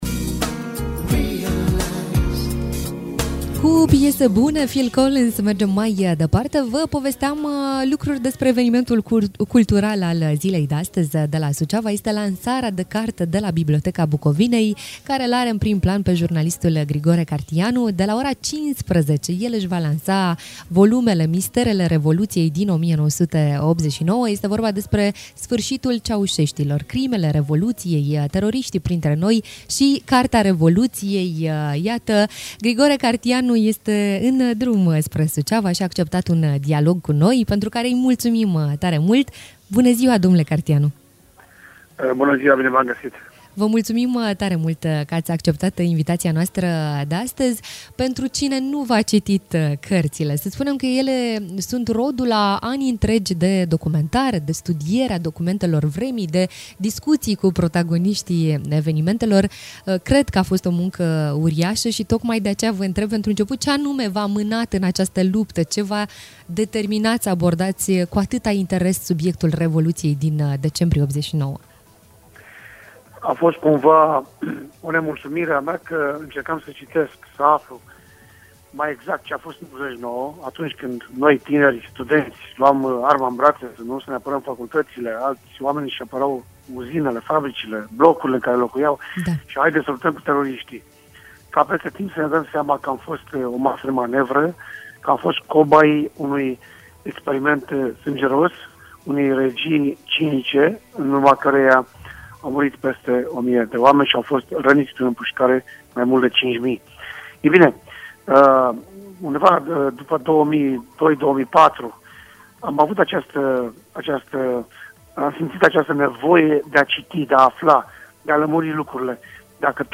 Interviu-Cartianu.mp3